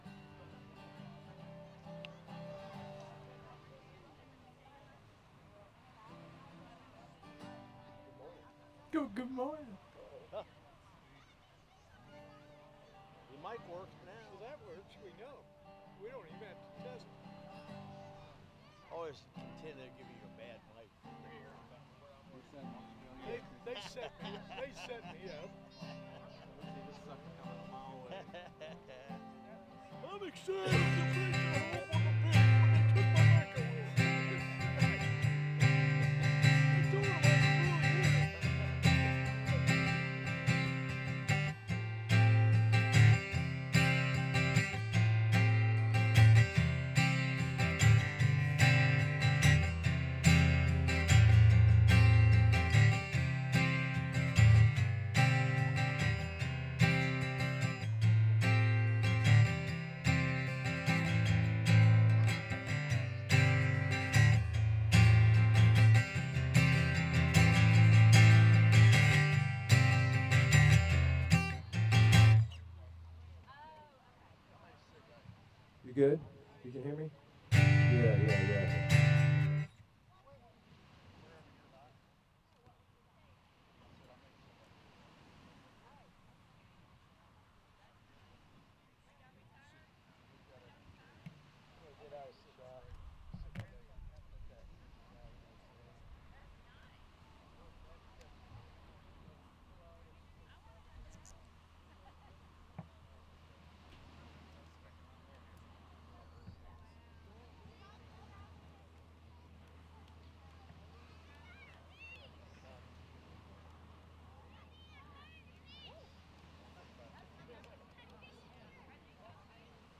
SERMON DESCRIPTION Today, we take a moment to reflect on the incredible resilience demonstrated by Apostle Paul, a truly inspirational example of a missionary.